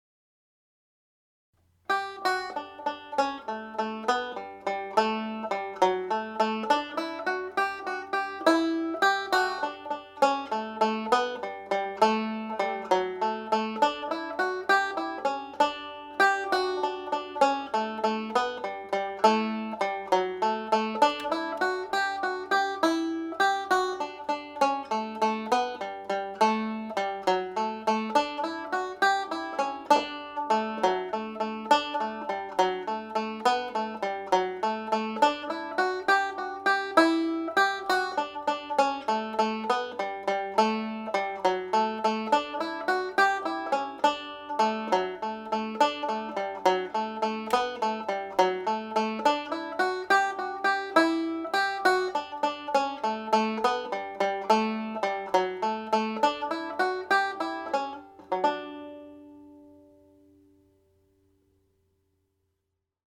Pure Banjo » Intermediate Level
My Darling Asleep played at jig speed